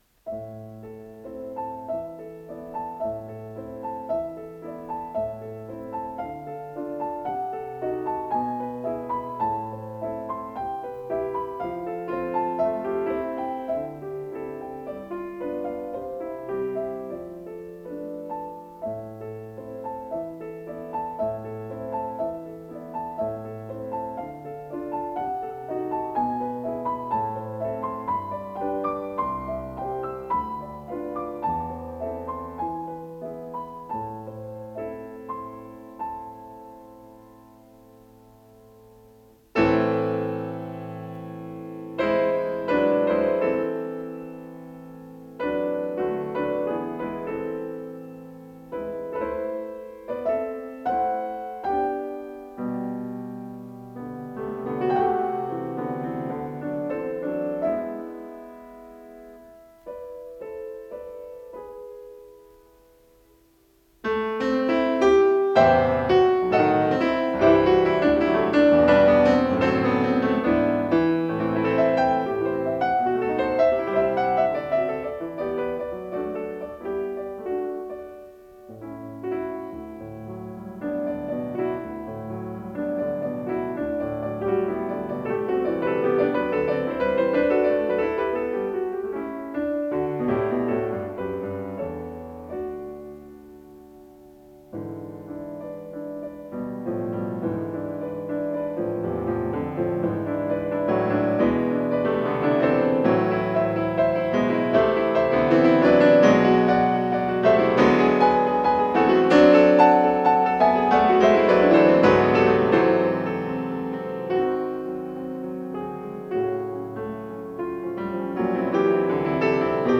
Исполнитель: Евгений Светланов - фортепиано
Название передачи Соната-воспоминание Подзаголовок Ля минор.
фортепиано